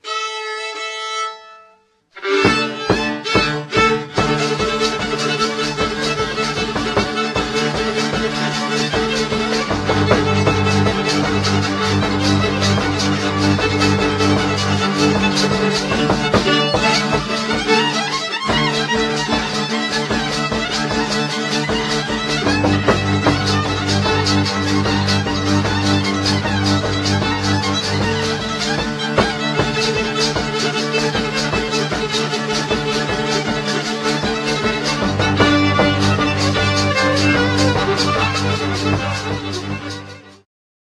Polka
Badania terenowe
skrzypce
basy 3-strunowe
bębenek